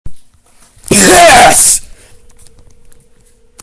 Piss